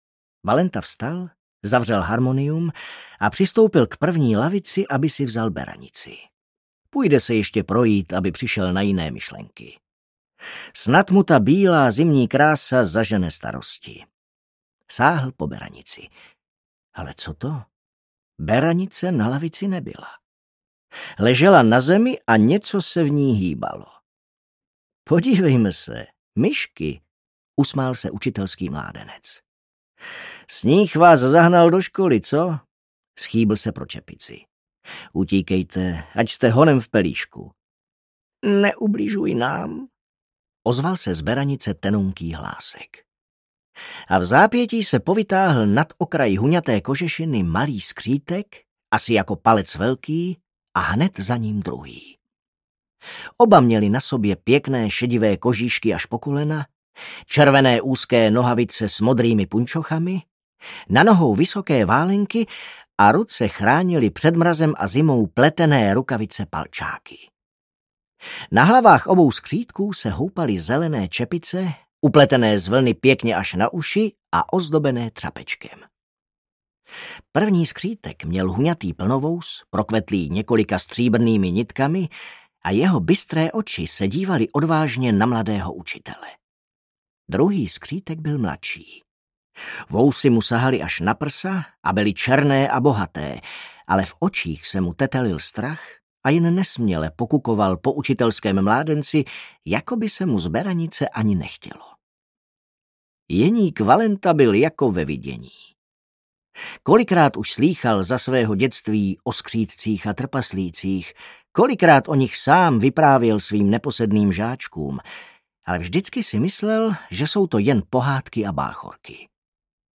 Zlaté dukáty audiokniha
Ukázka z knihy
zlate-dukaty-audiokniha